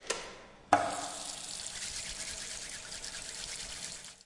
Campusupf 2013 " 洗手
描述：人在UPF的厕所里洗手。声音是用Zoom H2录制的。用Audacity软件对声音进行规范化处理并引入淡入/淡出。
Tag: 肥皂 校园UPF UPF-CS13 洗涤